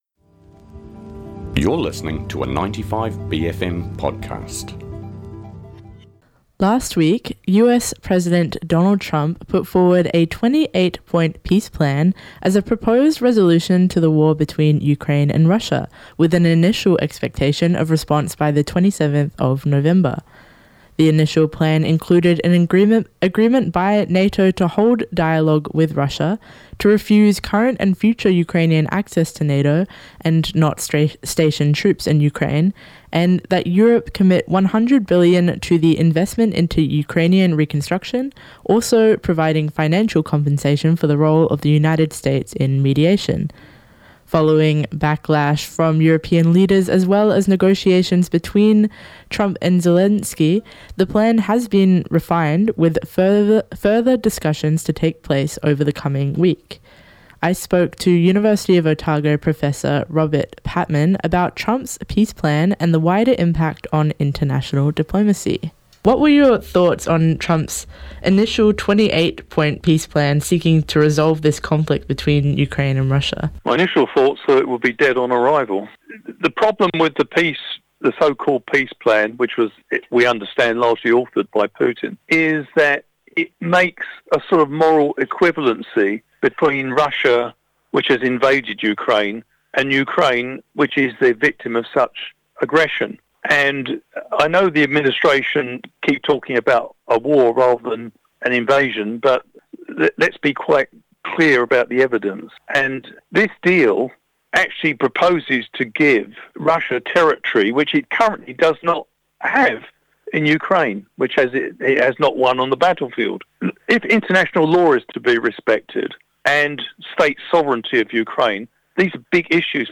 News & Current Affairs show